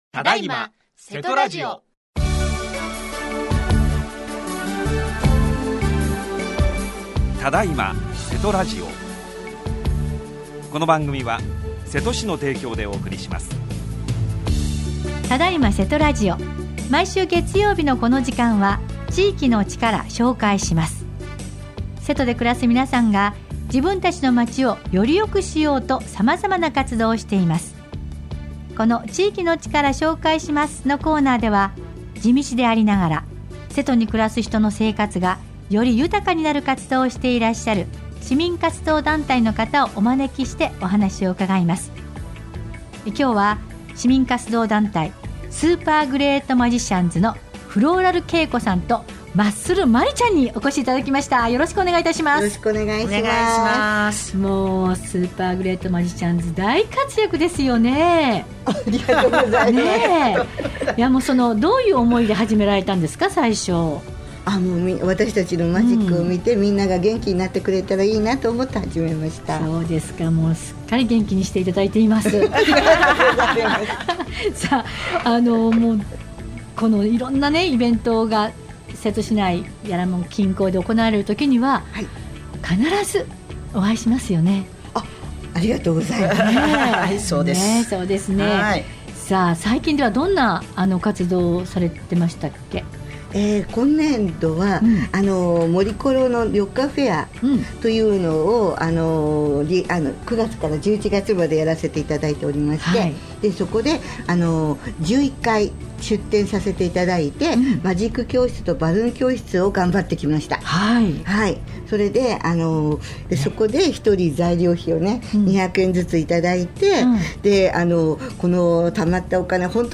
このコーナーでは、地道でありながら、 瀬戸に暮らす人の生活がより豊かになる活動をしていらっしゃる 市民活動団体の方をお招きしてお話を伺います。